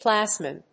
/ˈplazmɒn(英国英語)/